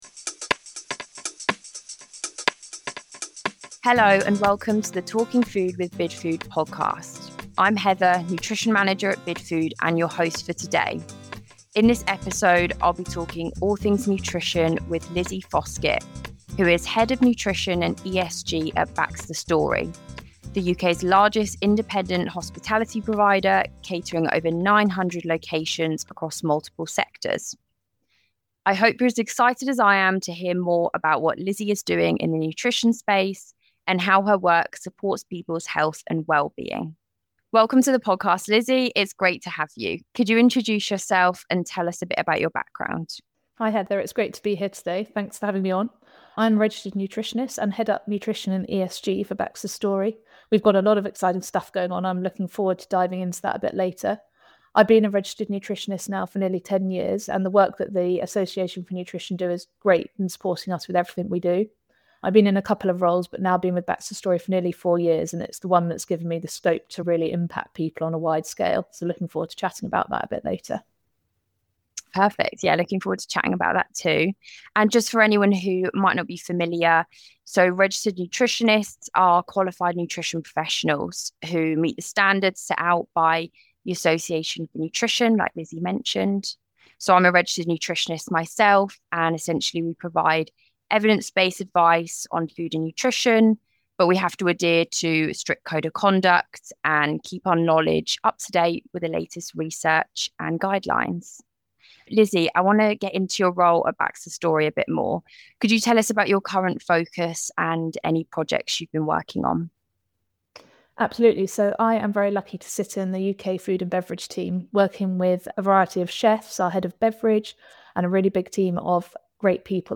Tune in for an insightful conversation, practical tips and expert advice to help you sup